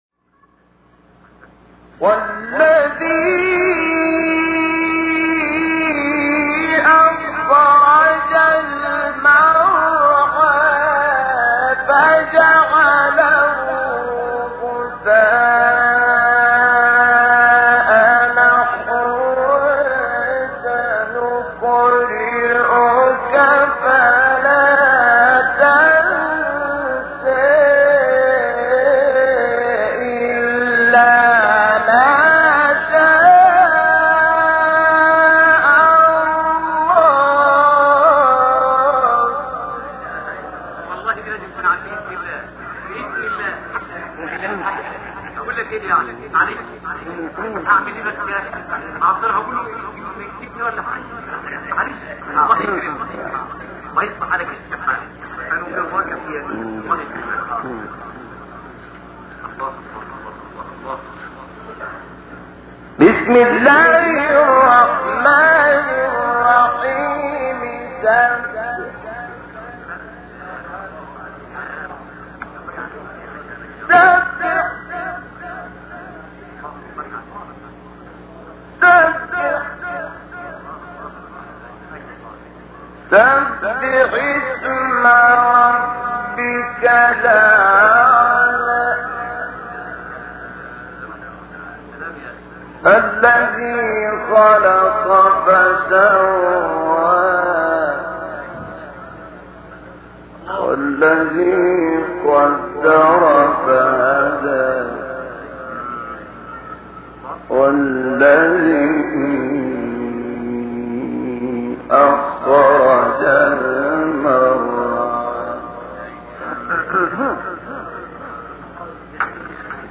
تلاوت سوره اعلی توسط استاد شحات انور | نغمات قرآن | دانلود تلاوت قرآن